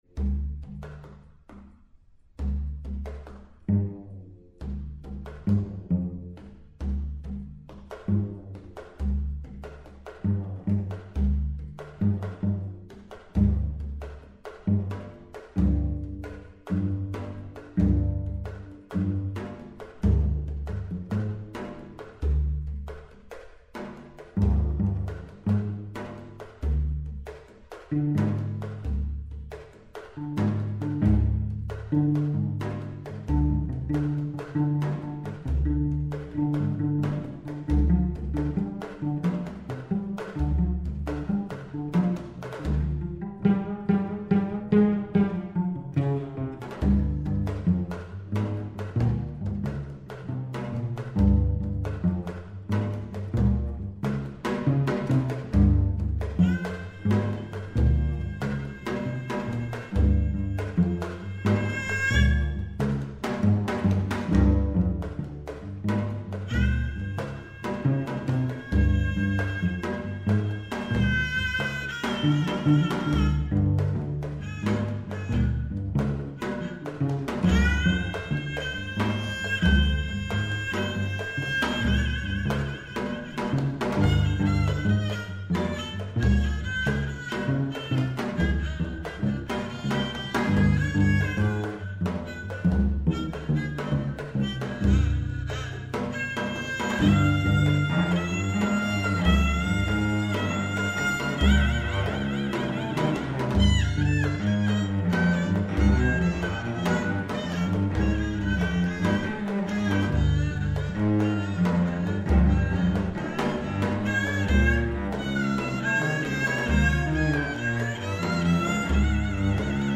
haegeum, cello, janggu